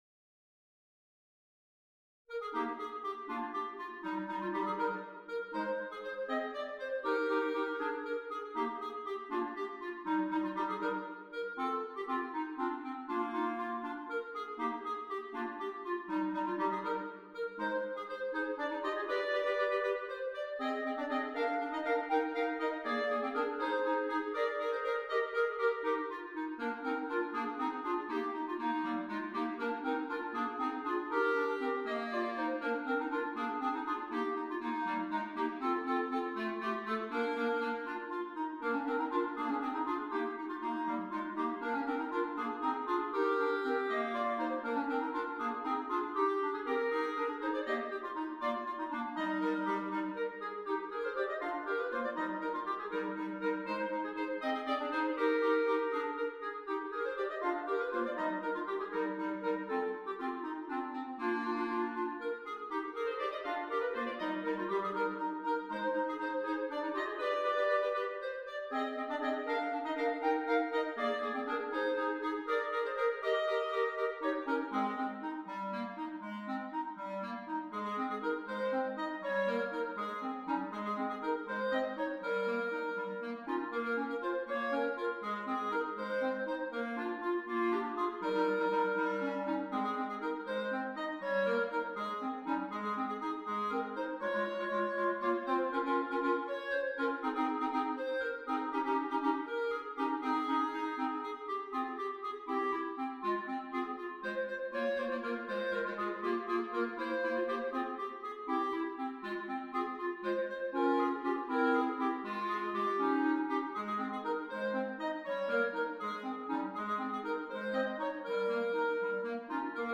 3 Clarinets